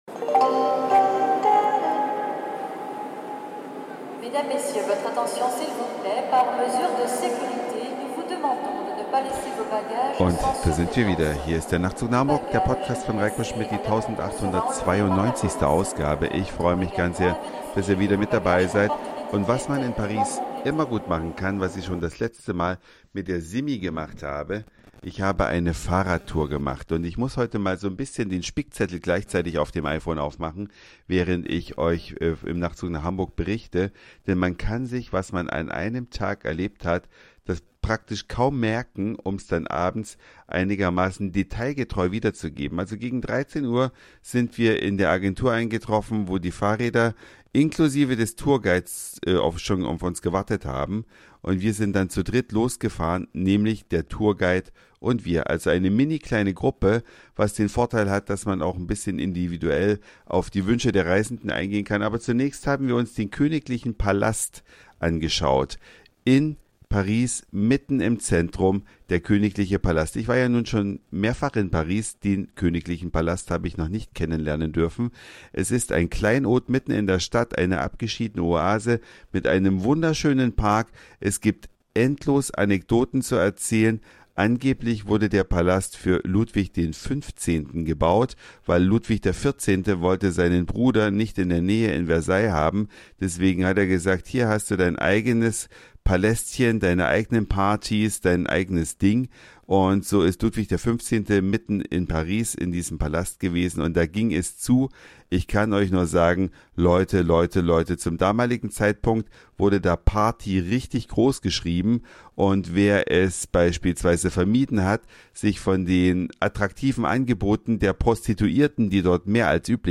Eine Reise durch die Vielfalt aus Satire, Informationen, Soundseeing und Audioblog.
Fahrradtour durch Paris Der Louvre